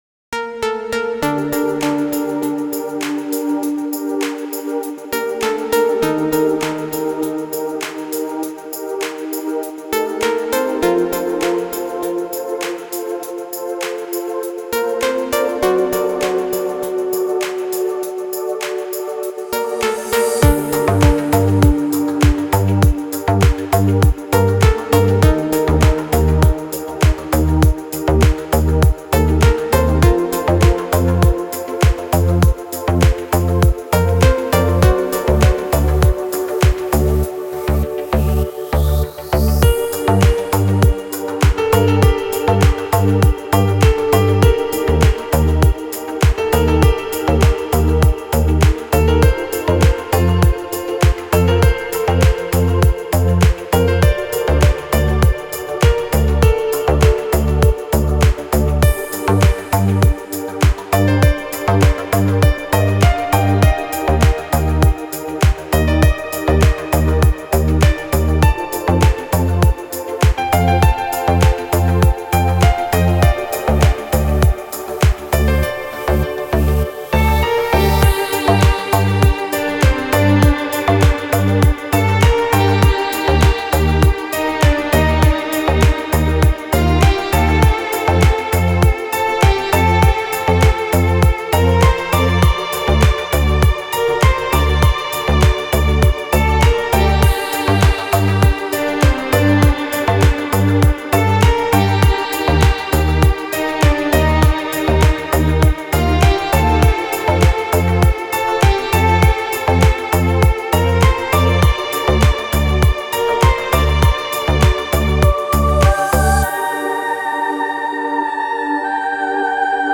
موسیقی بی کلام دیپ هاوس ریتمیک آرام